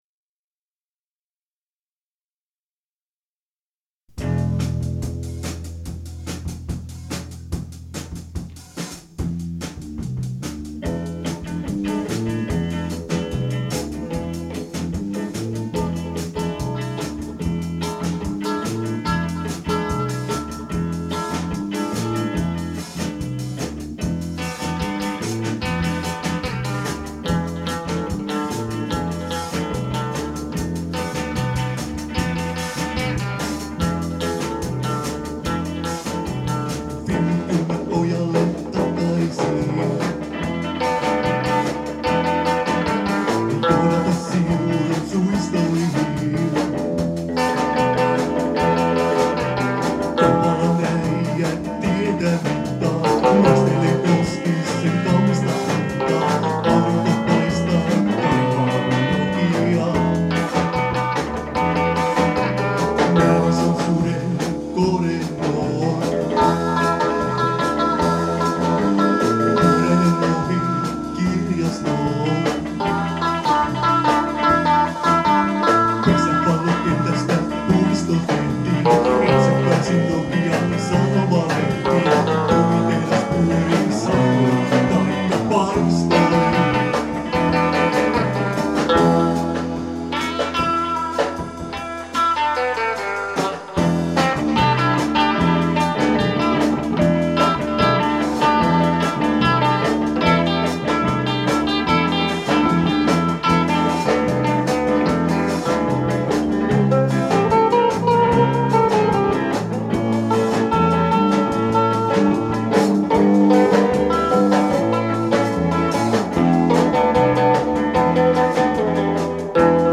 Äänitetty treenikämpällä 2003